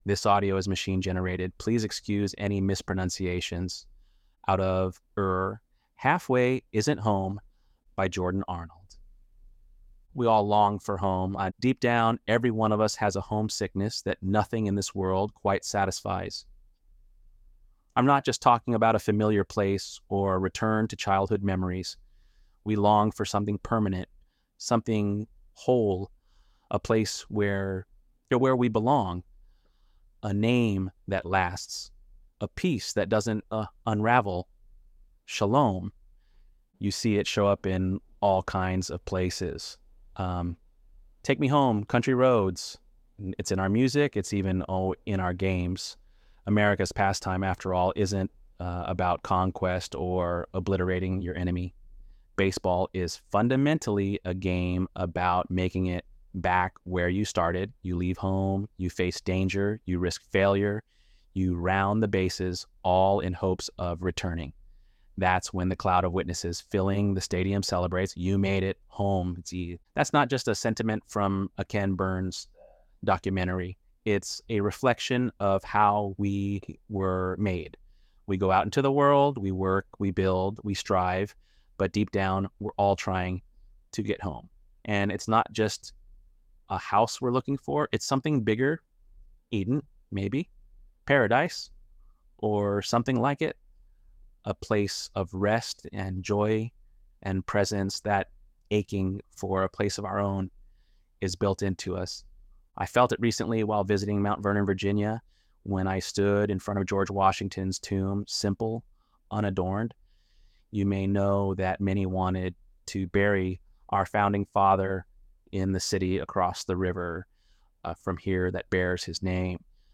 ElevenLabs_8.26.mp3